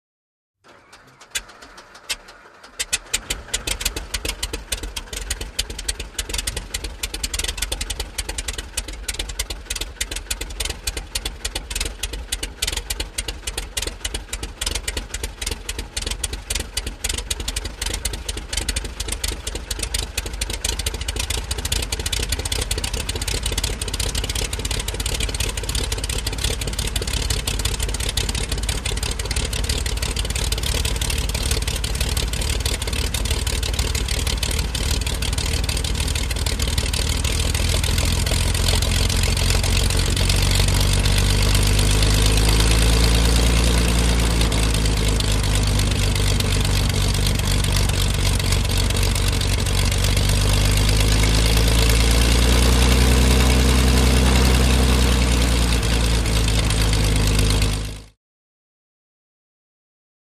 Beechcraft: Start / Idle: Low; Beechcraft, 1978 E55 Baron. Interior Perspective. Grind And Sputter To Start, Blast Of Fuel Ignition, Steady Idle With Quiet Rudder And Mechanical Movement, Rev Up And Taxi. Close Perspective. Prop Plane.